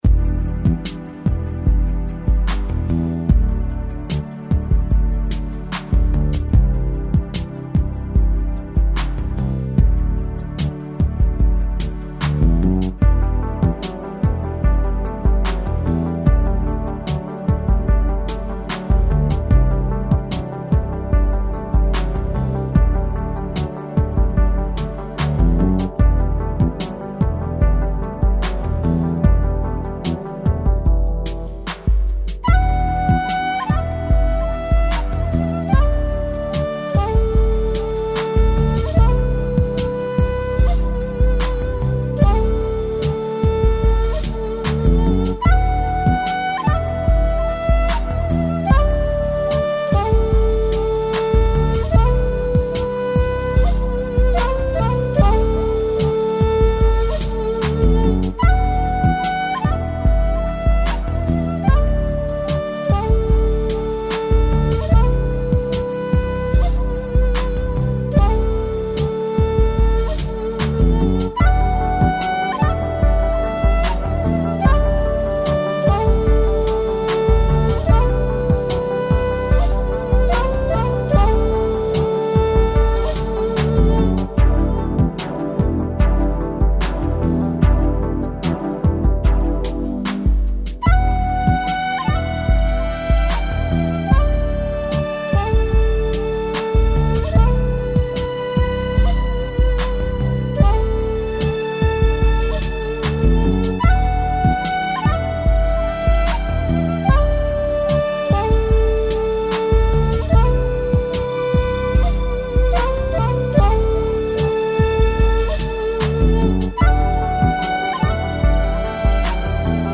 Прошу опознать инструмент, солирующий в данной композиции.
Синтезатор. А звук флейты сику.
Живая панфлейта звучит с большим продуваемым воздухом.ЭТО- синтетика.
Это однозначно синтезатор.